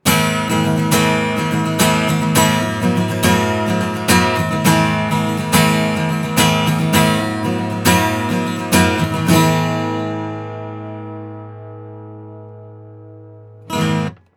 【サンプル音源あり】コンデンサーマイクの王道！NEUMANN U87AI
音質は、若干高音に味付けがされている印象ですが、非常にナチュラルです。音の情報量も多いですがやや芯が細い感じもあります。
実際の録り音
アコースティックギター
87-アコギ.wav